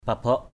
/pa-bɔ˨˩ʔ/ (đg.) thấm nước = imbiber d’eau. pabaok aia ka baok F%_b<K a`% k% _b<K thấm nước cho bã ra.
pabaok.mp3